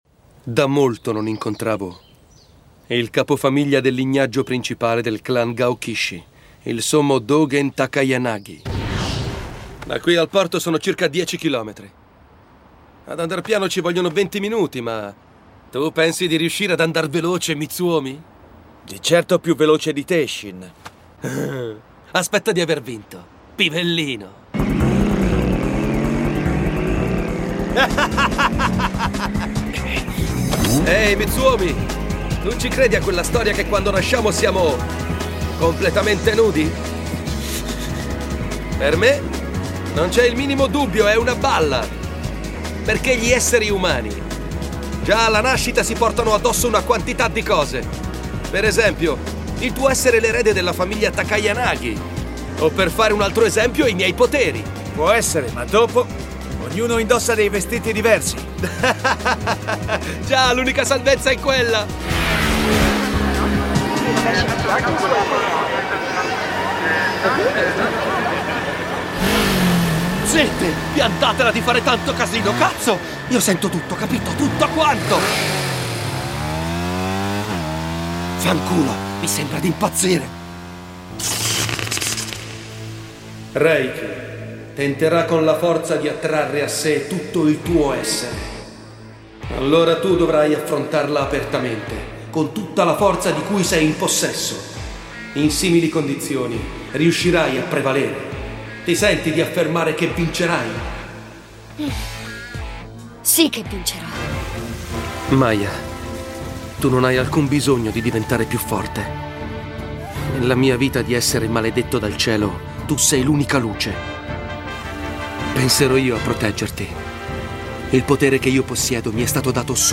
dal cartone animato "Inferno e Paradiso", in cui doppia Shin Natsume.